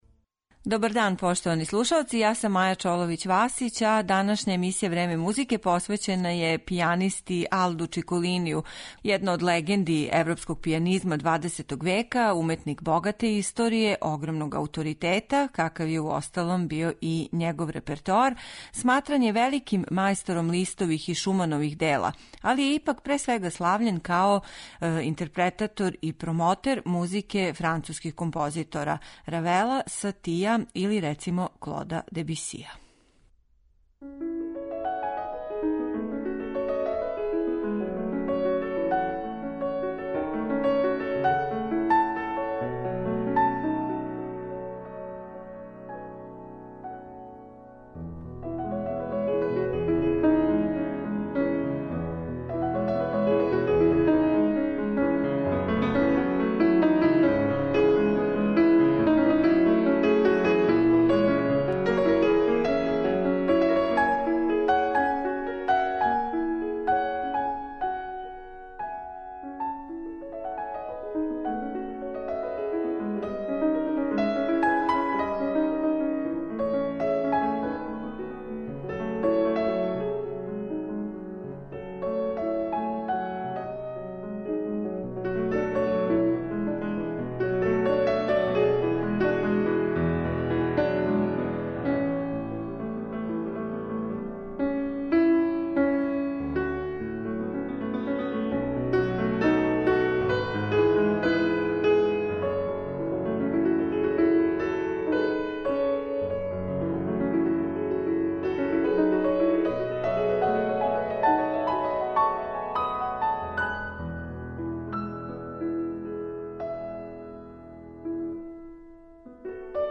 Део тог богатог реперотара уметника кога је више пута слушала и београдска публика, бићете у прилици да чујете у емисији